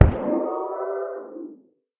Minecraft Version Minecraft Version snapshot Latest Release | Latest Snapshot snapshot / assets / minecraft / sounds / mob / guardian / guardian_death.ogg Compare With Compare With Latest Release | Latest Snapshot
guardian_death.ogg